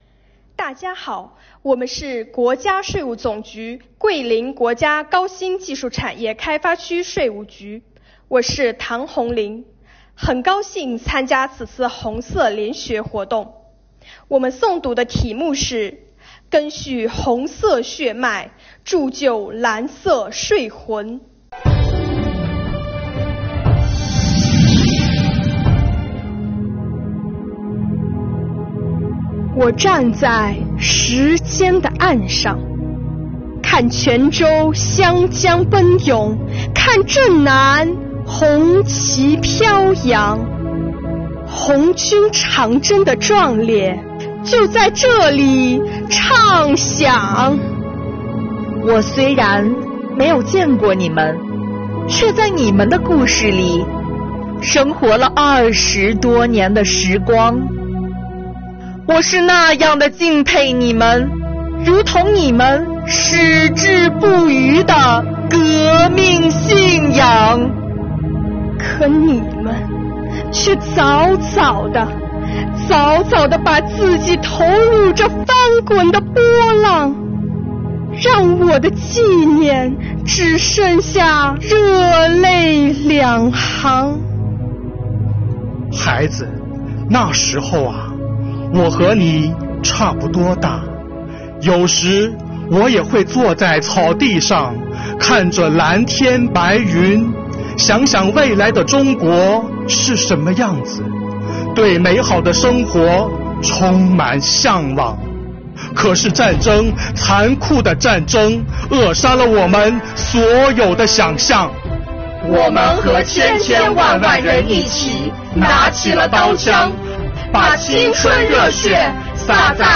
诗朗诵丨赓续红色血脉 铸就蓝色税魂